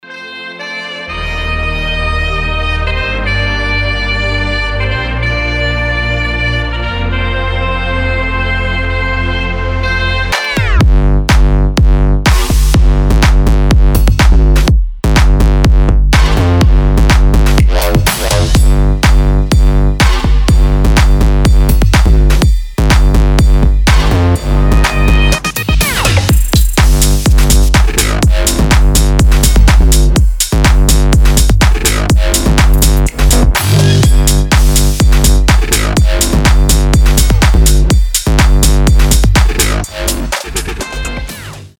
Саксофон и басы - рингтон